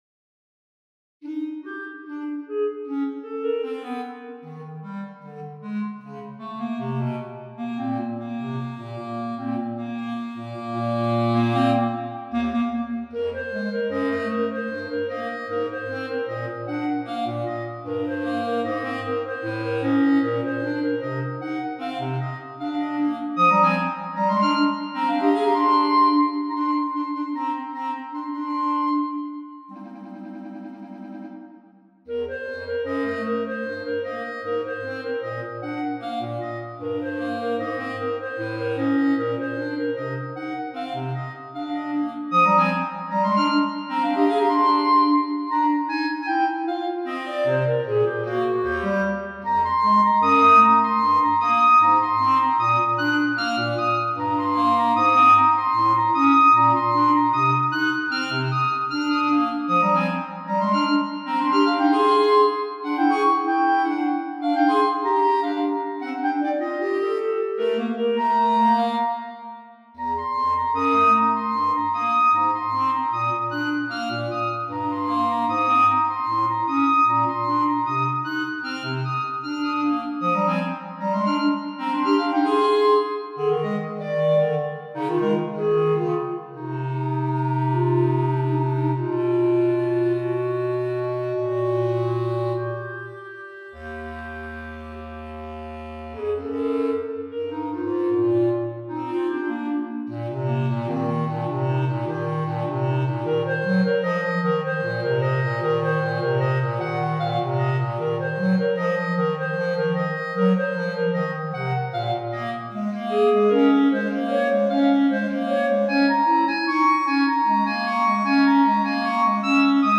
A little ragtime flavored fugue for clarinet and bass clarinet, the subject stated as a rag and then passed back and forth in interlocking imitation at several time intervals and related tonal domains.
5 pages, circa 4' 45" - an MP3 demo is here:
Rag_Fugue_for_Clarinet_and_Bass_Clarinet.mp3